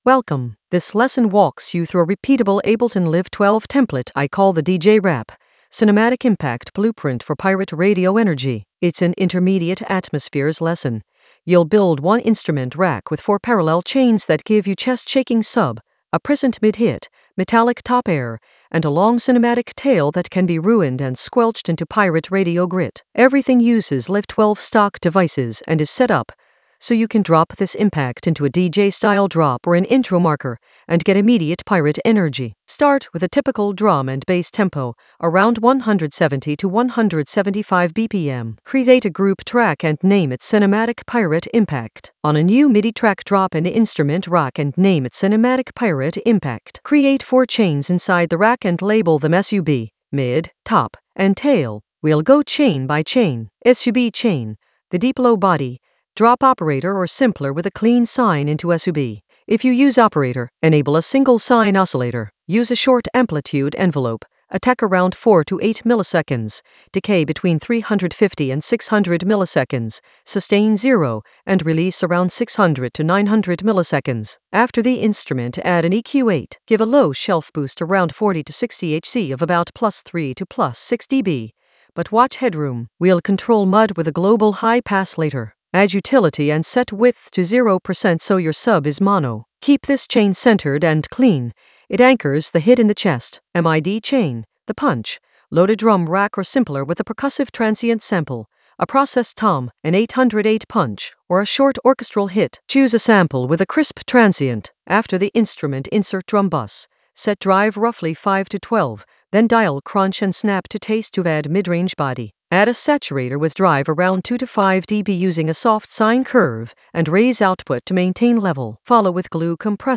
An AI-generated intermediate Ableton lesson focused on DJ Rap Ableton Live 12 cinematic impact blueprint for pirate-radio energy in the Atmospheres area of drum and bass production.
Narrated lesson audio
The voice track includes the tutorial plus extra teacher commentary.